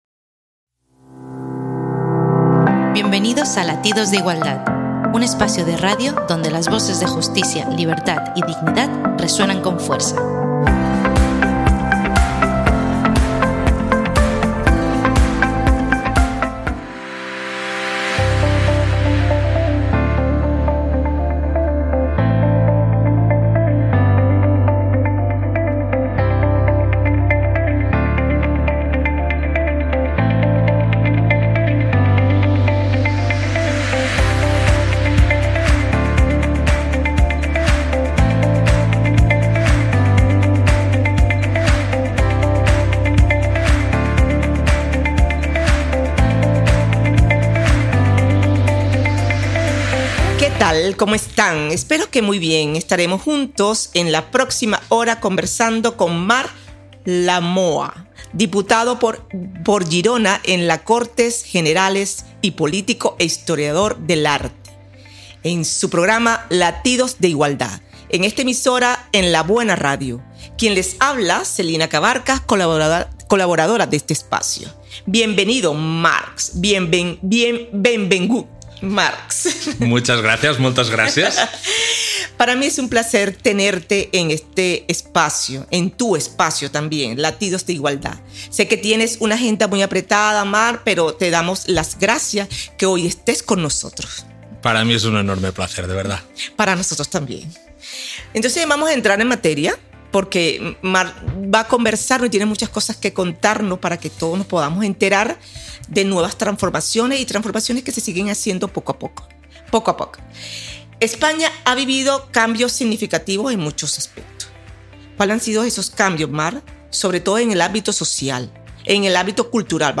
Entrevista a Marc Lamuà (Diputat PSC). Escoltar per comprendre, legislar per cuidar - En la buena radio